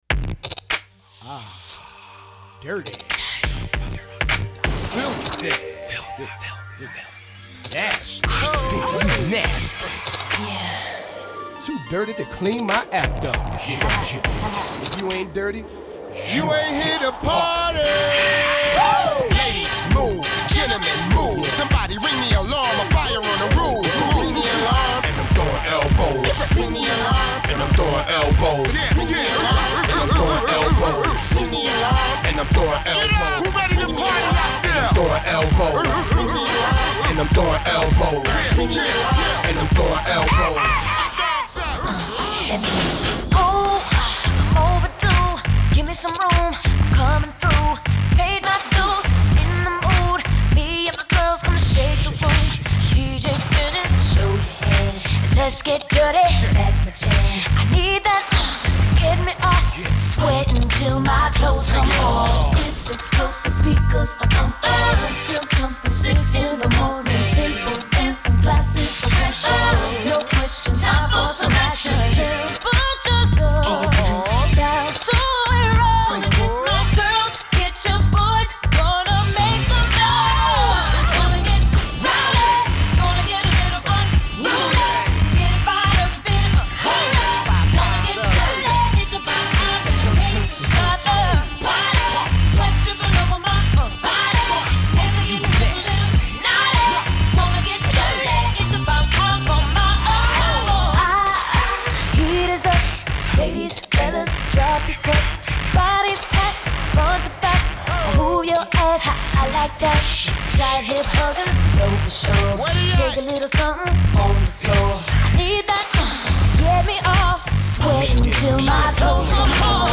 Pop 2000er